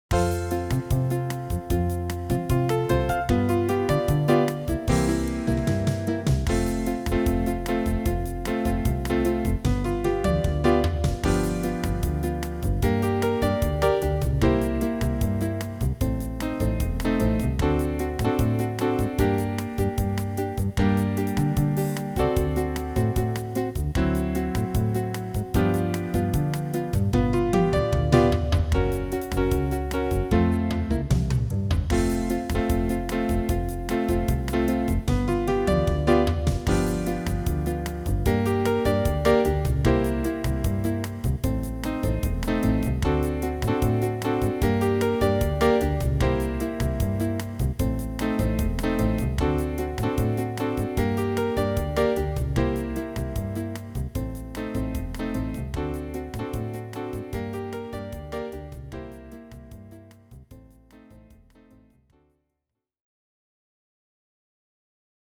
Version instrumentale